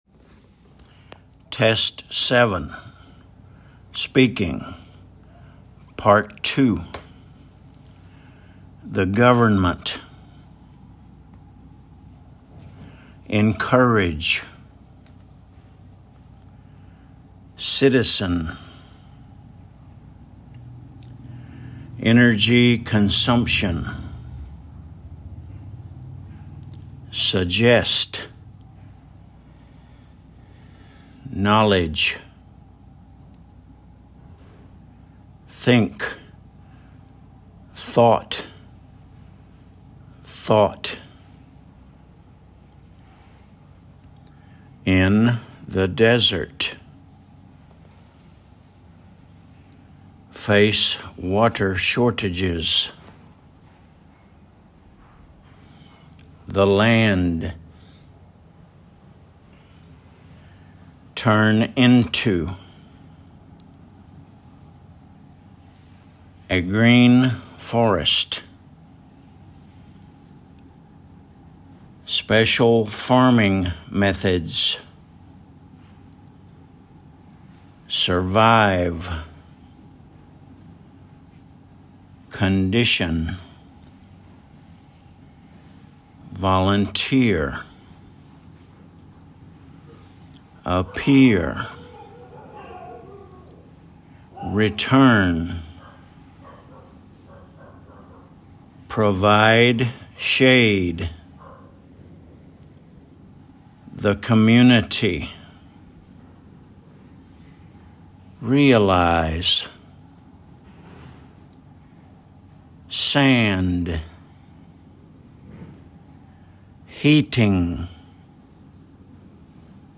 encourage /ɪnˈkʌrɪdʒ/
energy consumption /ˈɛnədʒi kənˈsʌmpʃən/
face water shortages /feɪs ˈwɔːtə ˈʃɔːtɪdʒɪz/